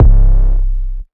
808_Kitchen_2.wav